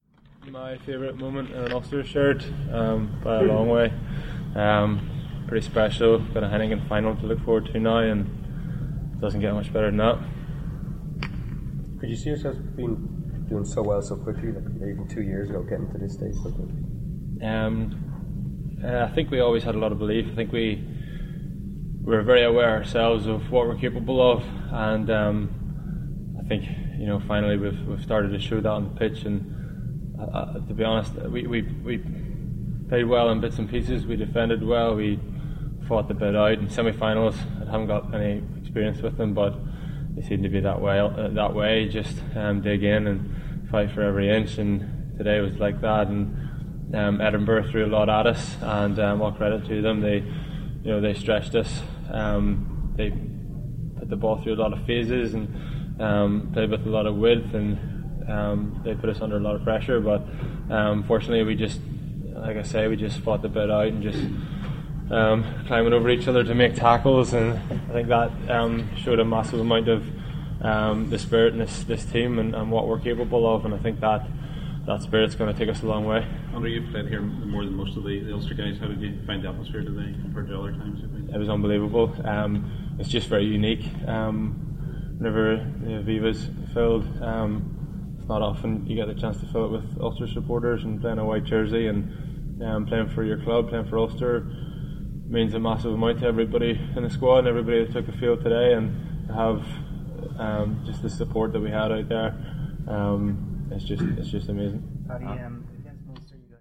Andrew Trimble talks to the media following Ulster's 22-19 victory over Edinburgh.